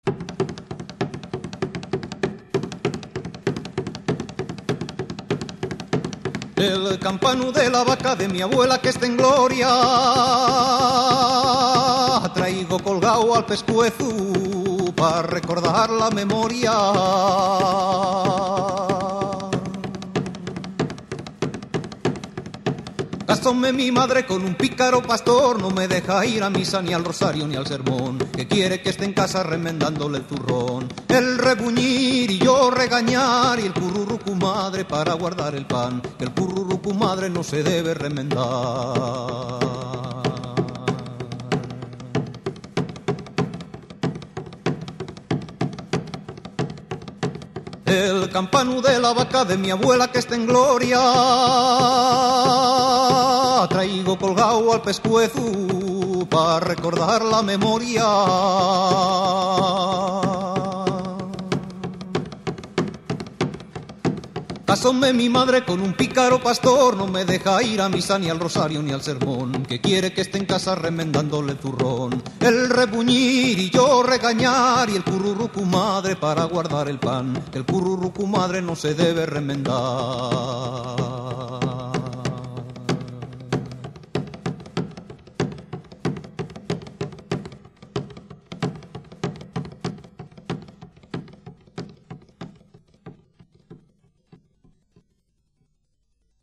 Pandero y voz